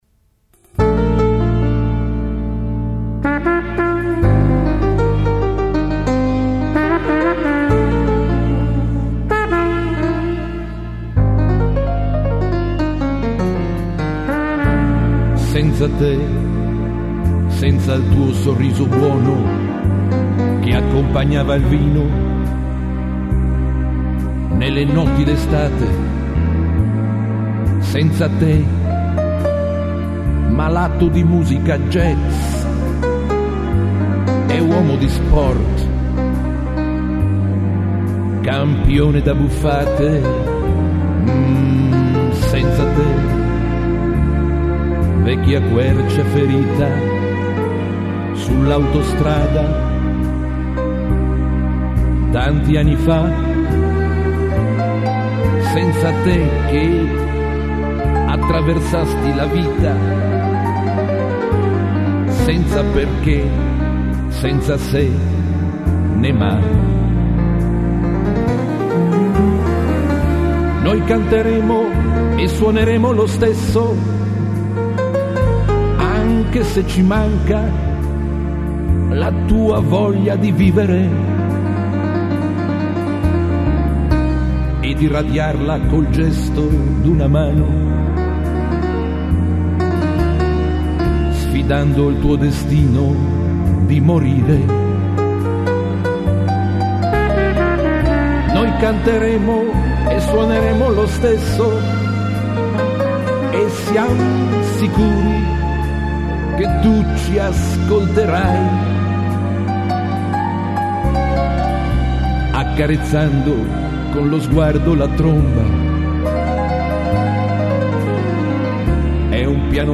nella sala di registrazione